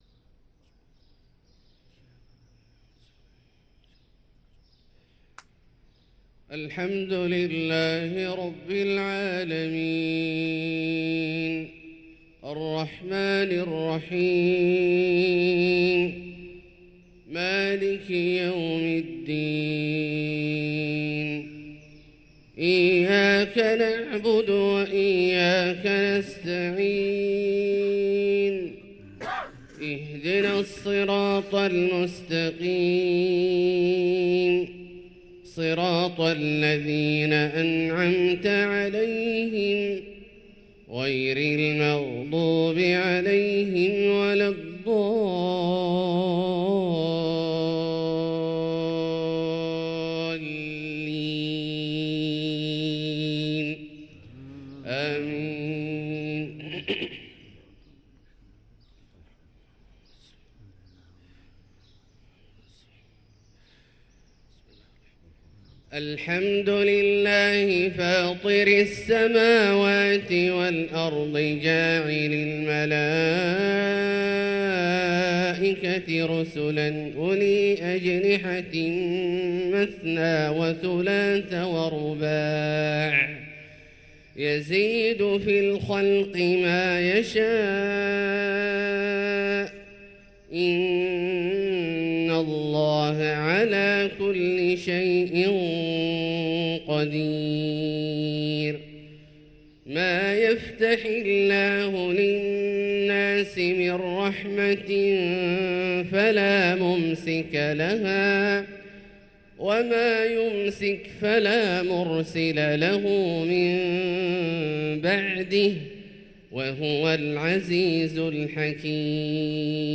صلاة الفجر للقارئ عبدالله الجهني 13 جمادي الآخر 1445 هـ
تِلَاوَات الْحَرَمَيْن .